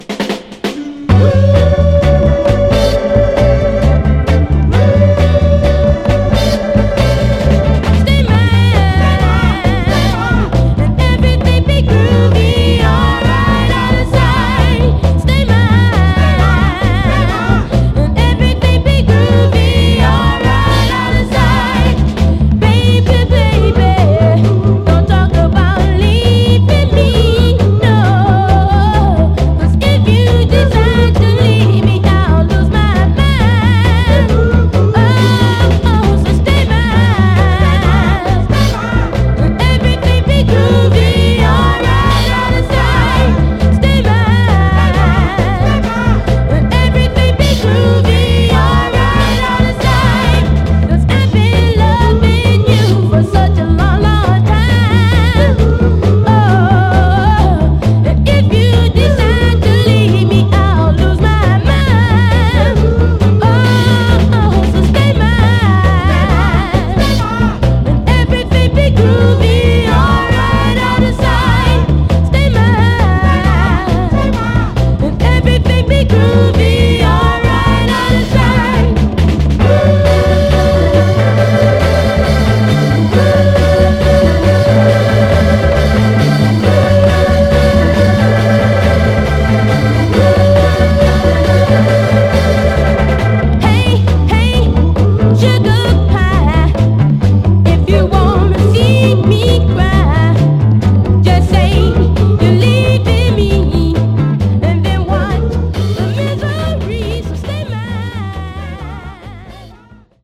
バブルガムで高揚感のあるヴォーカルで聴かせる、爆発力のあるノーザン・ソウル・ダンサーの人気曲です！
※試聴音源は実際にお送りする商品から録音したものです※